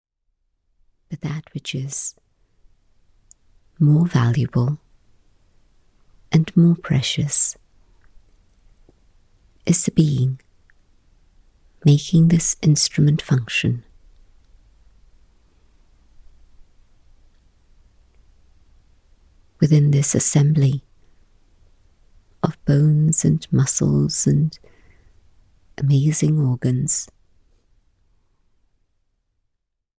Guided meditations to calm the mind and spirit